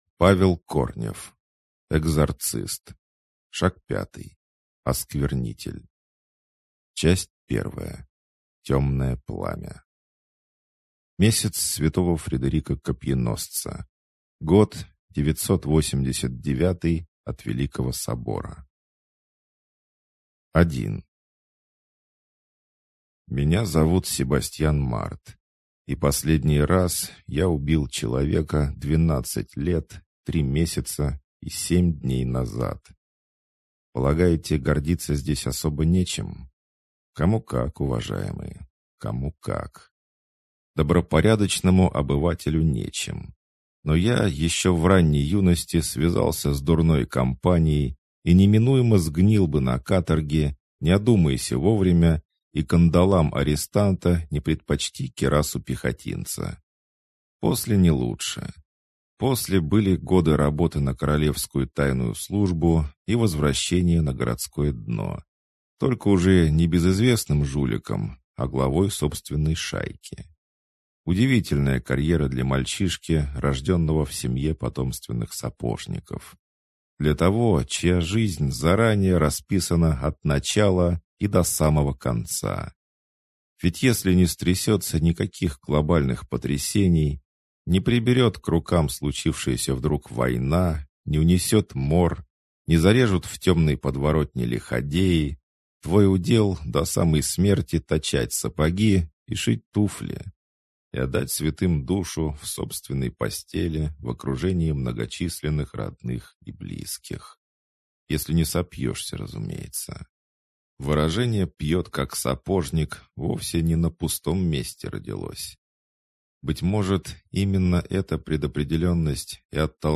Аудиокнига Экзорцист. Шаг пятый. Осквернитель | Библиотека аудиокниг